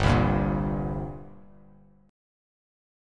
Gmbb5 chord